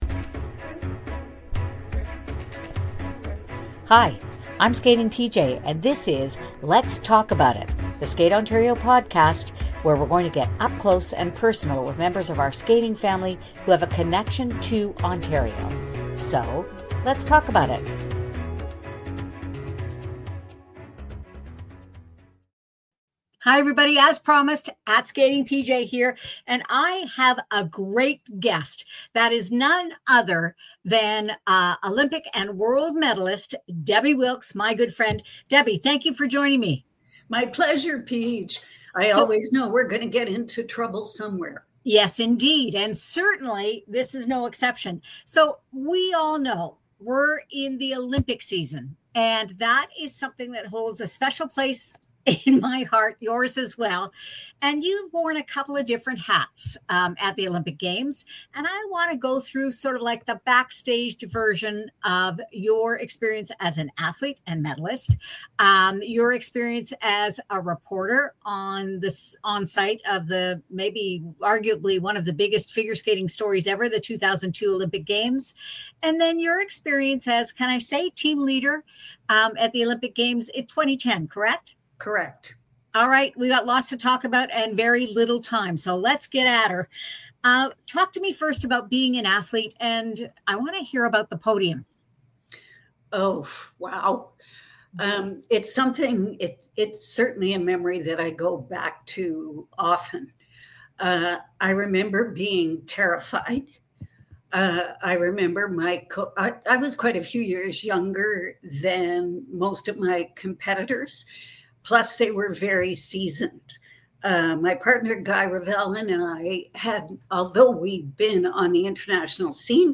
Get ready for an inspiring conversation filled with insight, history, and heart about being at the Olympic Games as an athlete, a reporter and a Team Leader .